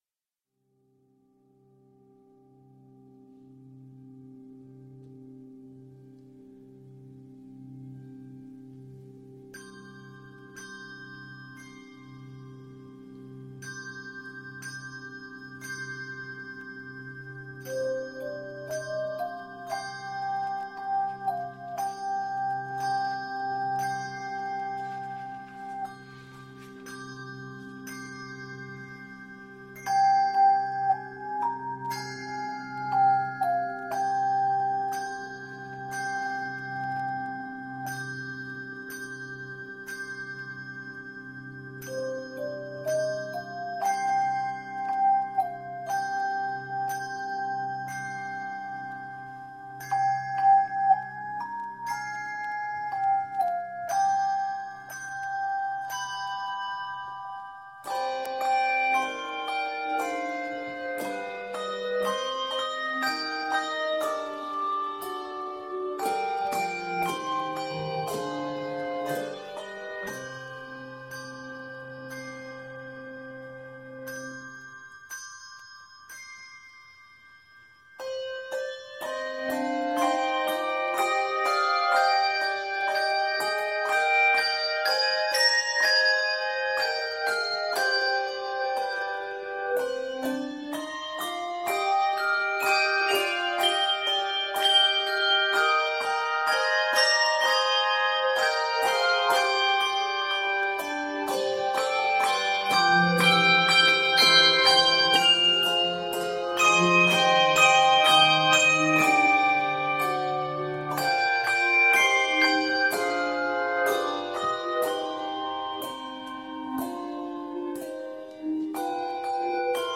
very worshipful setting
It is set in c minor and is 69 measures.